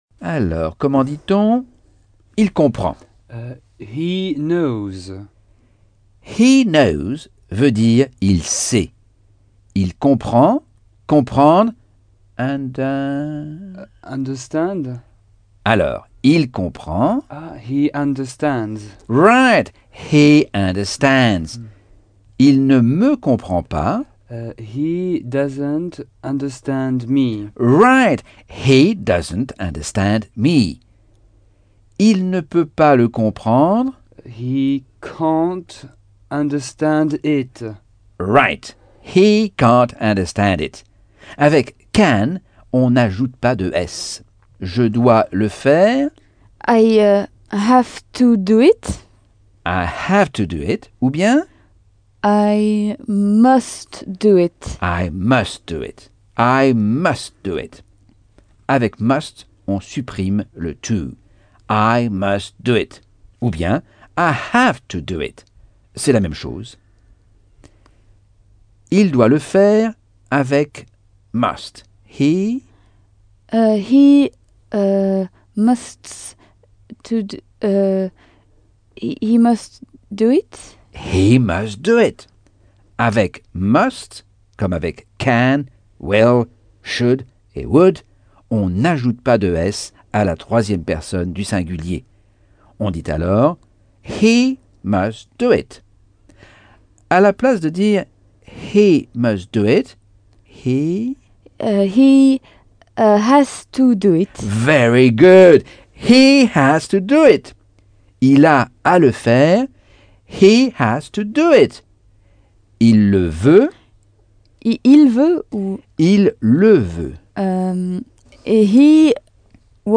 Leçon 2 - Cours audio Anglais par Michel Thomas - Chapitre 5